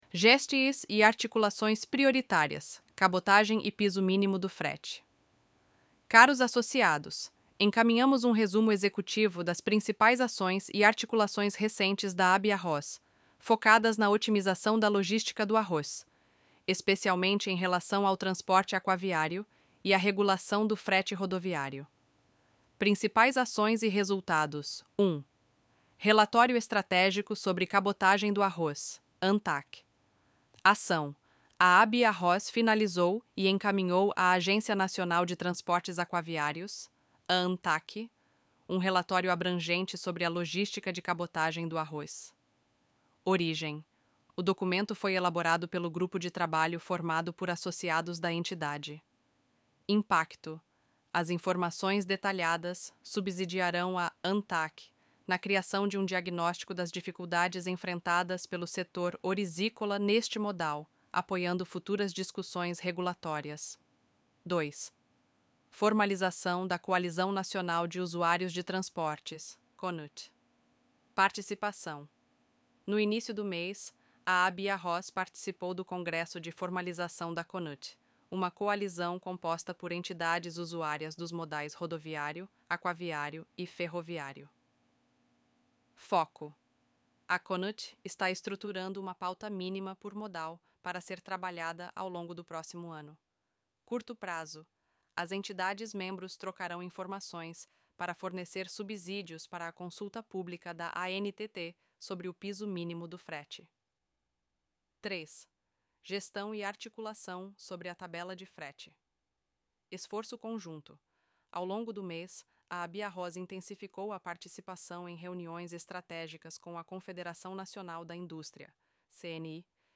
O áudio gerado por Inteligência Artificial podem conter falhas em pronúncias.